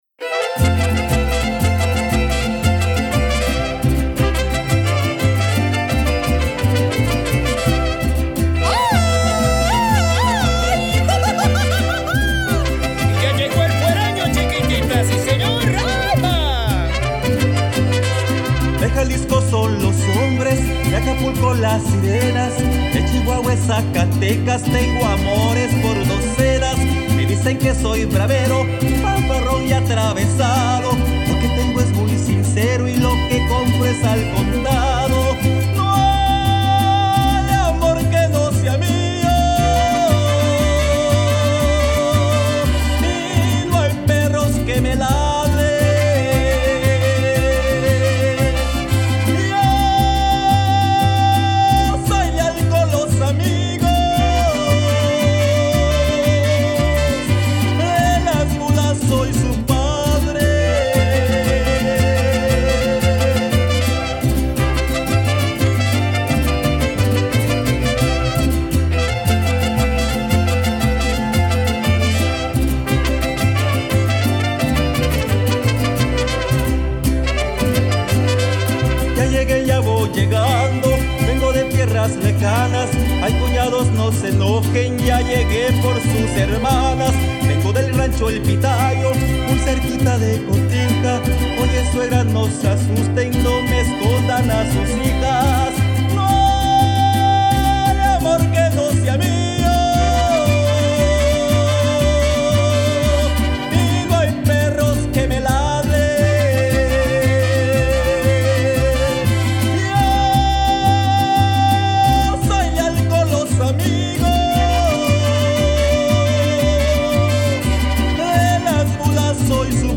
New-York-Mariachi-8-El-Fuereno.mp3